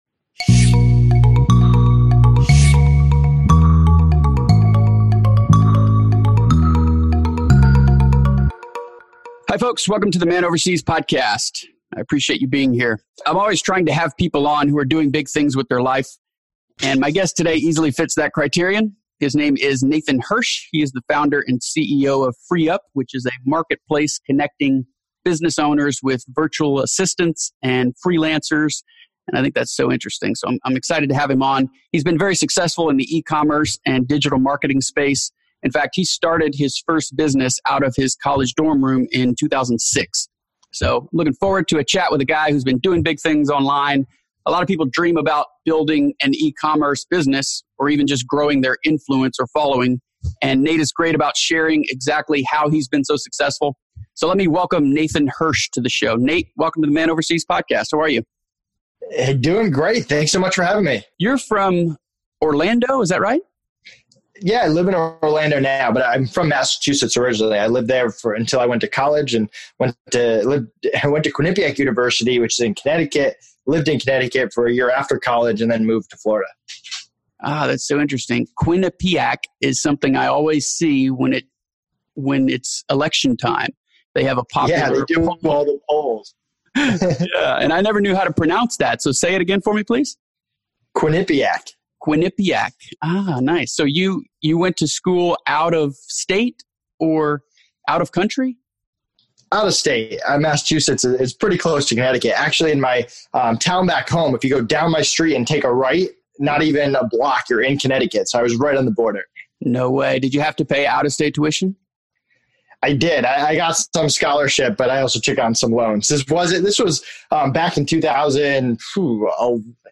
you can tell by the passion in his voice that despite all his early successes, he’s just getting started.